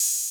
Open Hat (Uptown)(1).wav